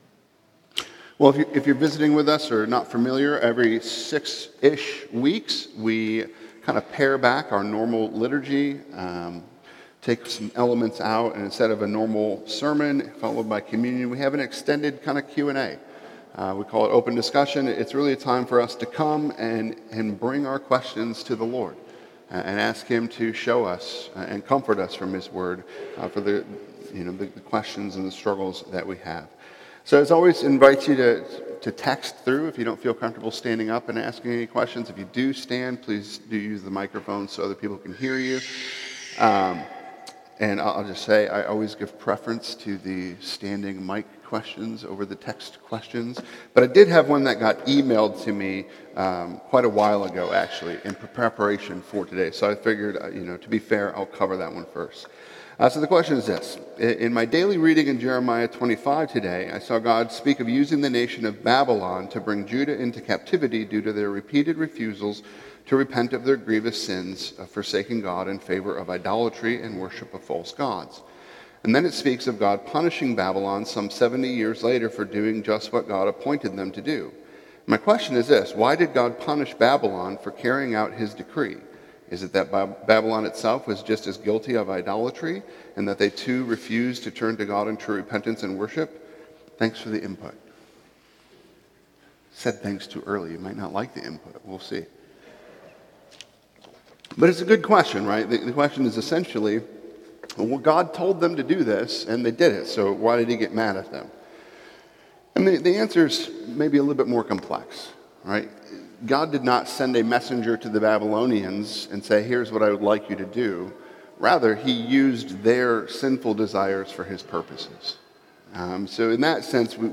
Weekly sermons from Grace & Peace PCA in Pittsburgh, PA.